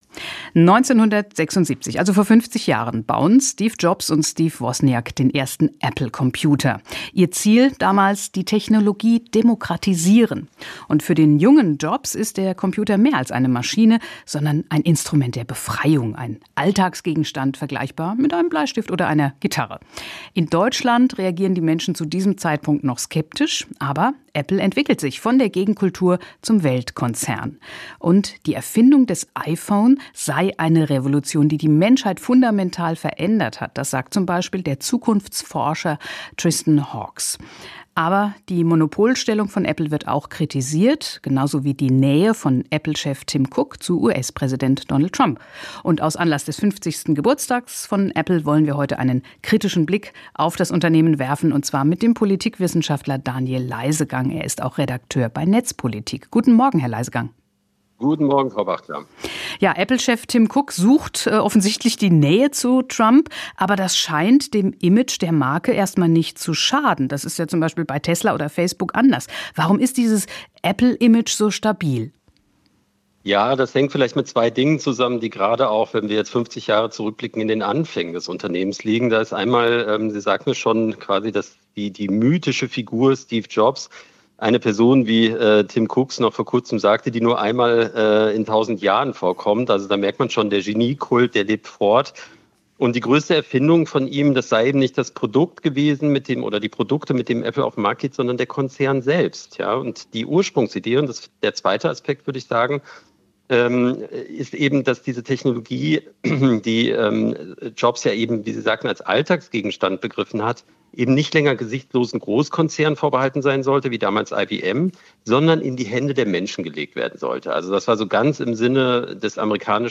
Politikwissenschaftler
im Gespräch